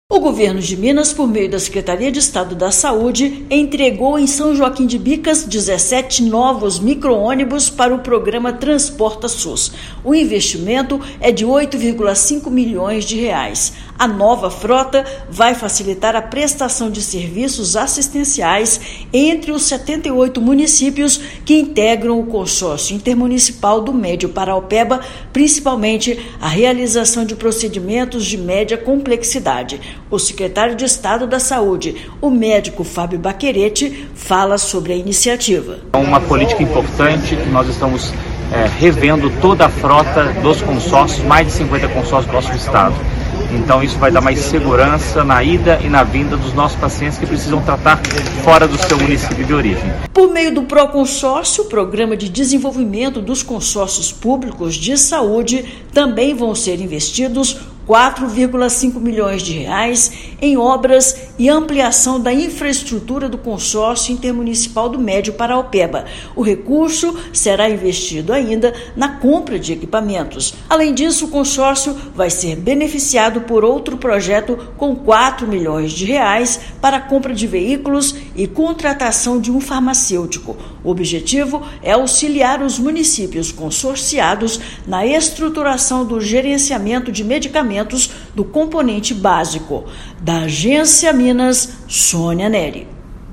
Com investimento de R$ 8,5 milhões, veículos vão facilitar a prestação de serviços assistenciais entre os 78 municípios que compõe o consórcio intermunicipal. Ouça matéria de rádio.